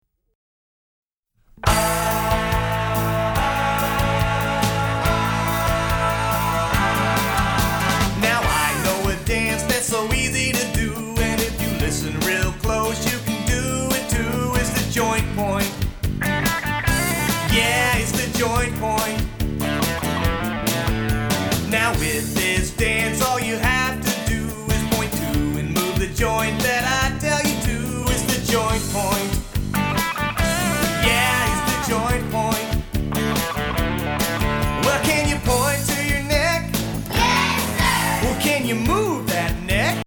exciting and upbeat Rock, Pop and Funk